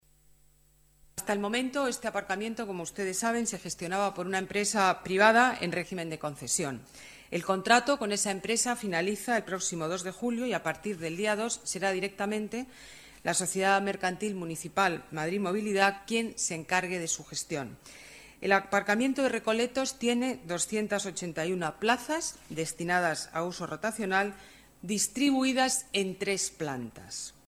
Nueva ventana:Declaraciones alcaldesa Ana Botella: Aparcamiento Recoletos